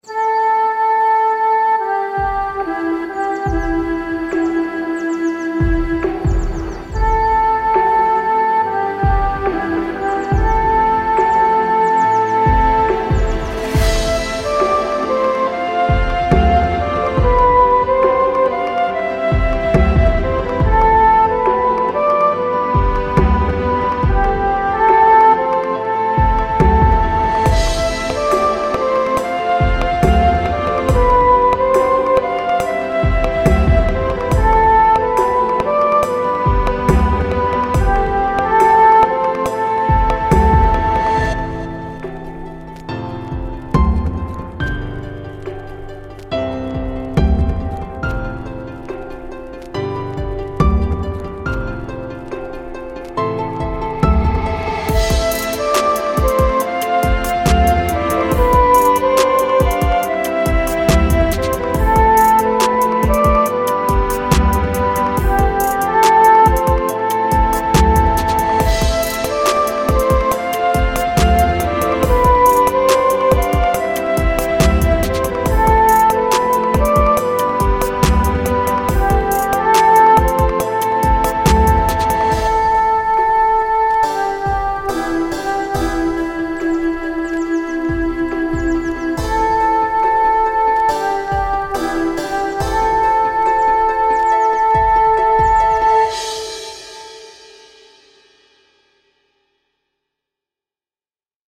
שילוב מעניין של מוזיקה קלאסית אם תופים מז’אנר עדכני…